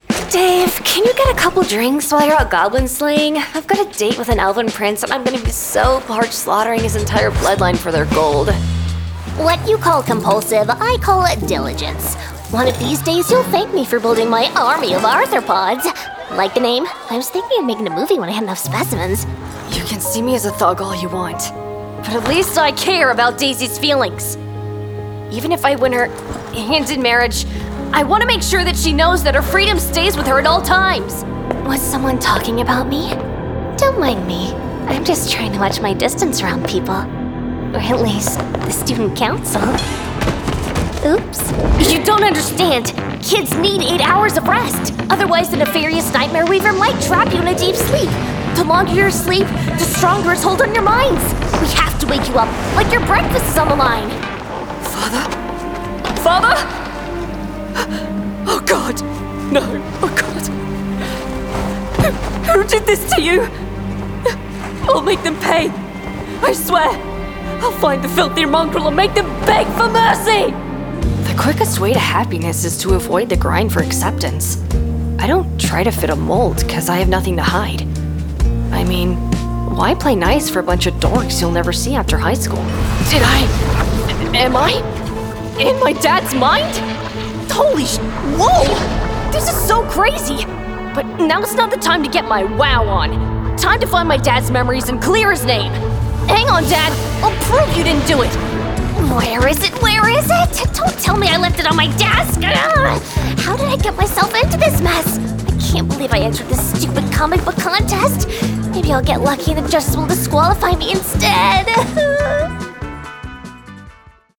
Demo
Child, Teenager, Young Adult, Adult, Mature Adult
character - british accent
character - scottish accent
character - us accent
animation
impersonations